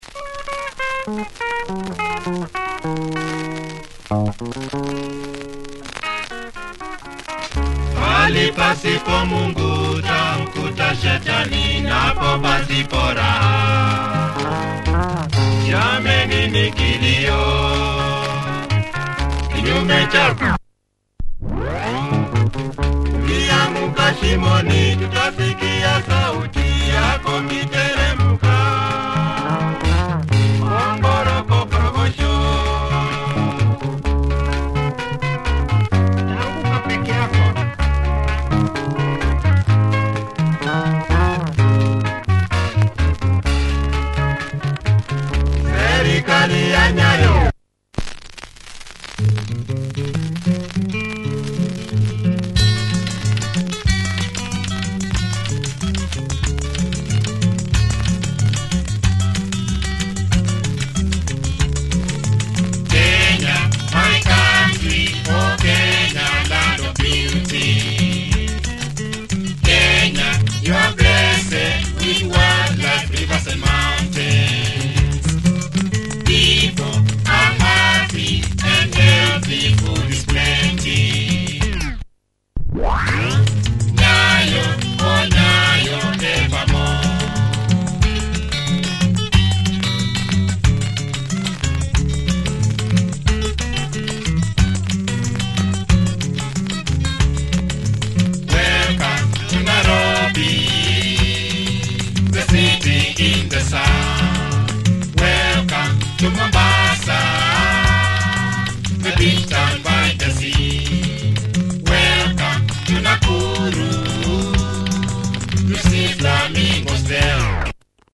Nice trad